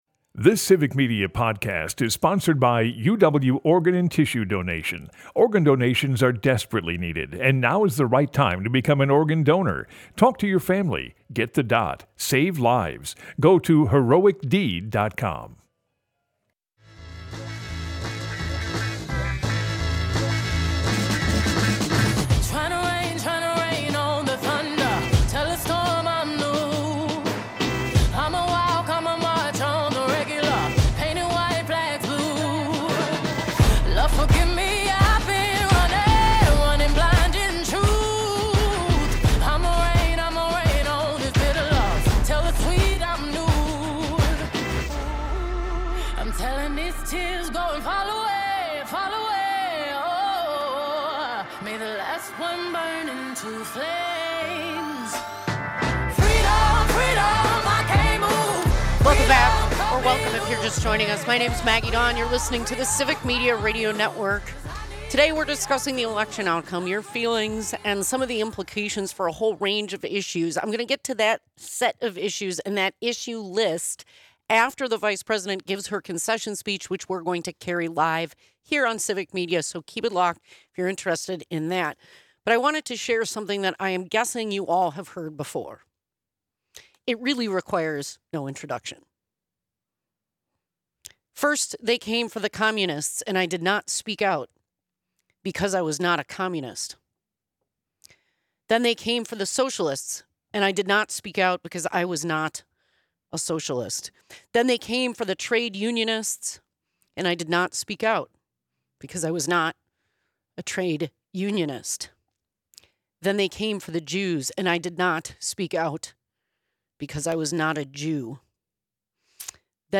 She takes your phone calls and shares news from the incoming election results.